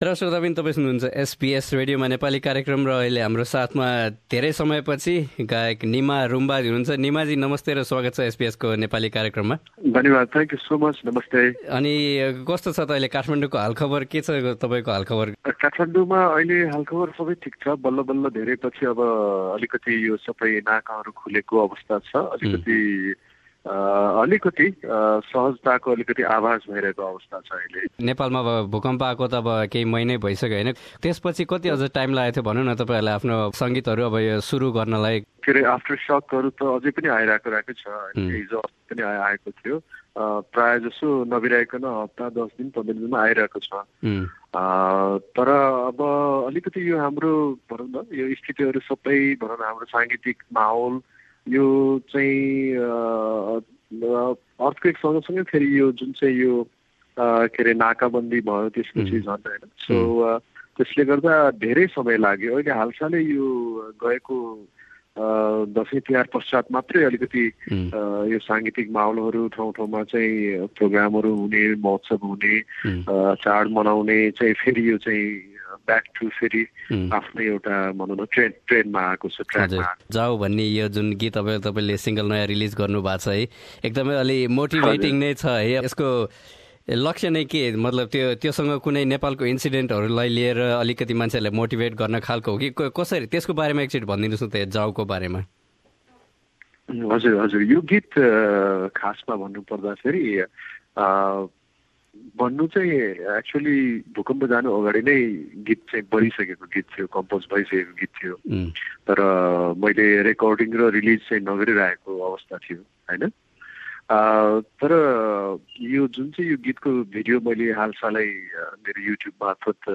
Nima Rumba talking about his new song Jau.